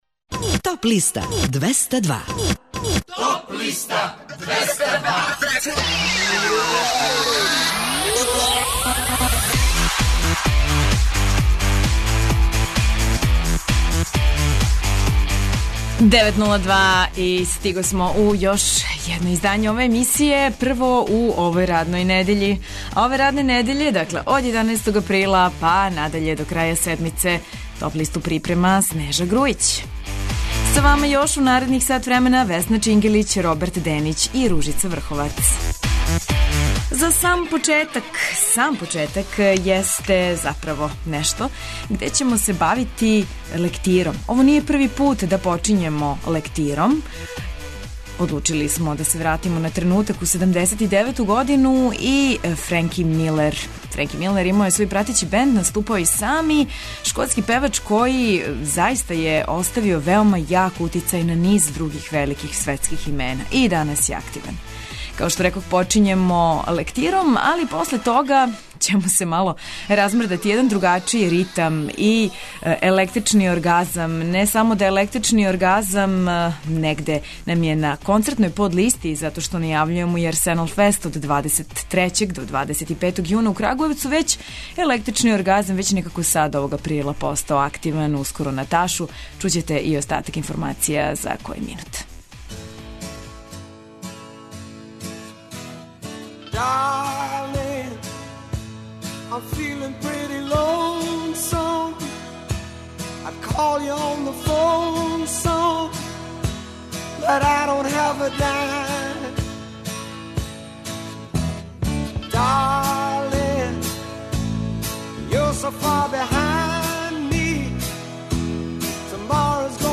преузми : 26.18 MB Топ листа Autor: Београд 202 Емисија садржи више различитих жанровских подлиста.